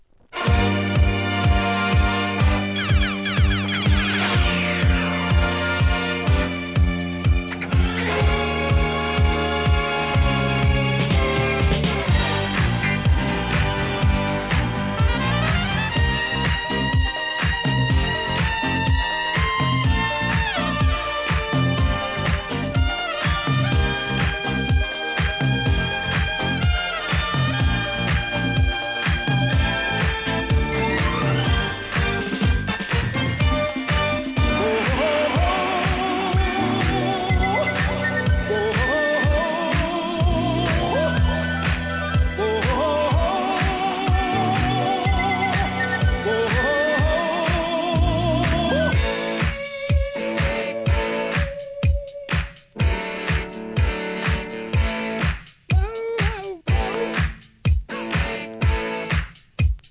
店舗 数量 カートに入れる お気に入りに追加 オランダの覆面グループによるDISCO HITメドレー!!